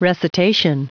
Prononciation du mot recitation en anglais (fichier audio)
Prononciation du mot : recitation